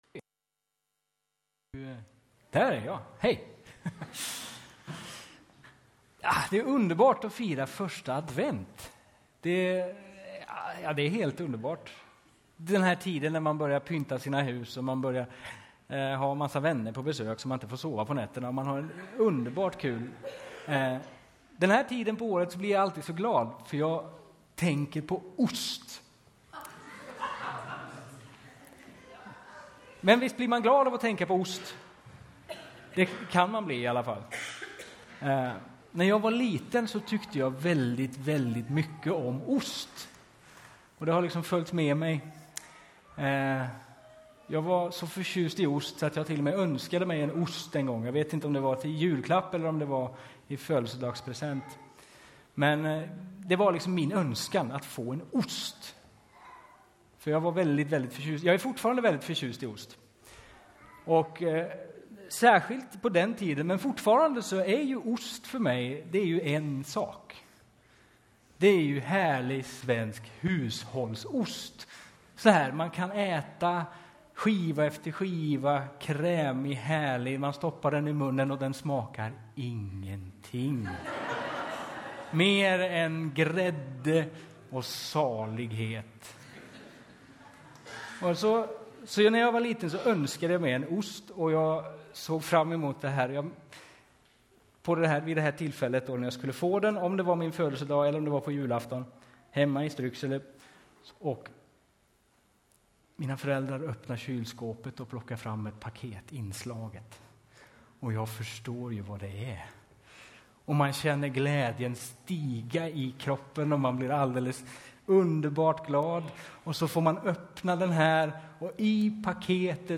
Predikoserie